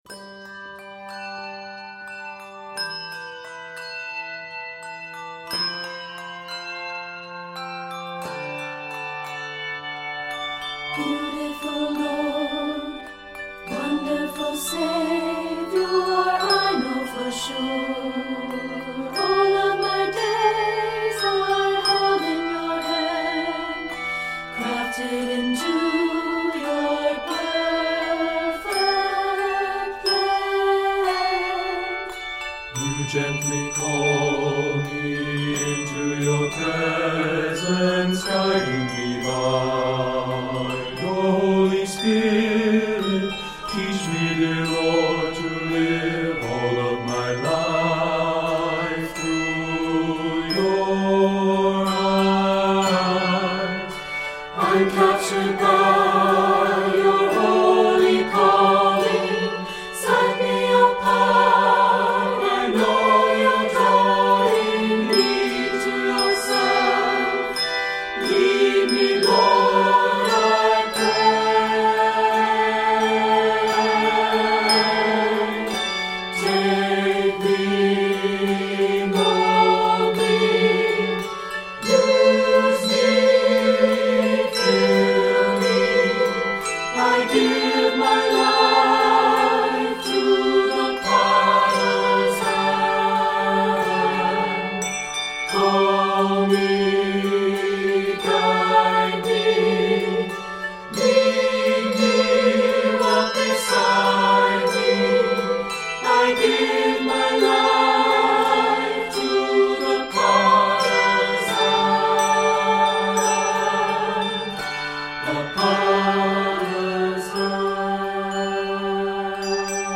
worshipful ballad
gently syncopated setting